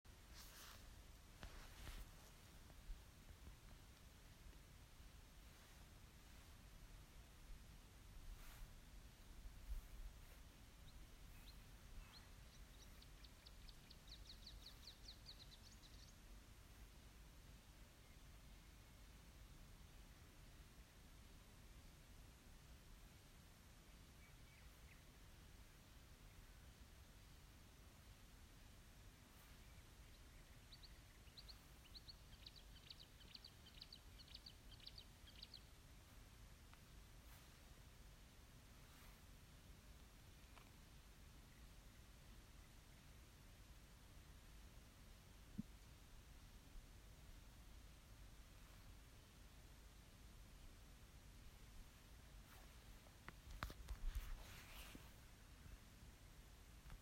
Птицы -> Славковые ->
болотная камышевка, Acrocephalus palustris
СтатусПоёт